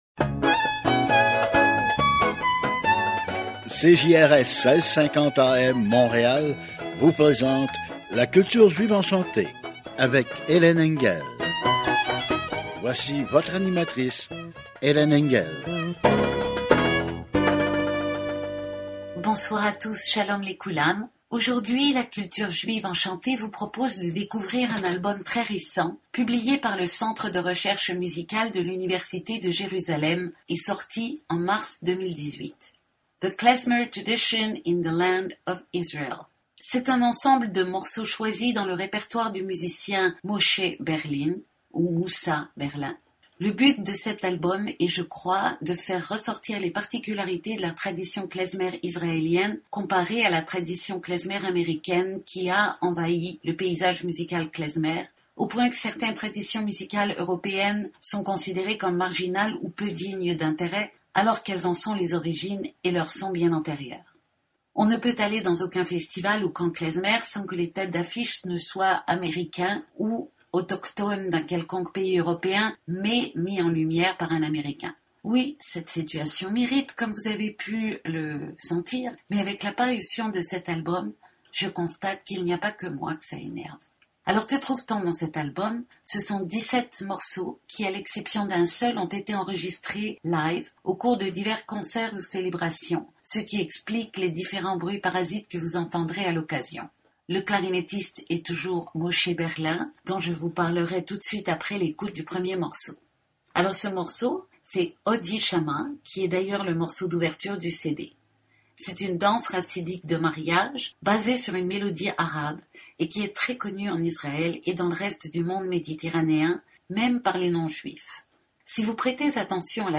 Les faces cachées de la musique juive – 2018,Radio SHALOM Montréal (1650 AM) Klezmer and Israeli music.
A radio Show broadcasted on Radio SHALOM Montreal